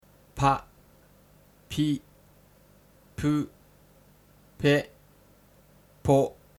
pa pi pu pe po